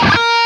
LEAD A#3 LP.wav